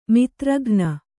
♪ mitraghna